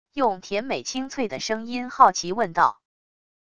用甜美清脆的声音好奇问道wav音频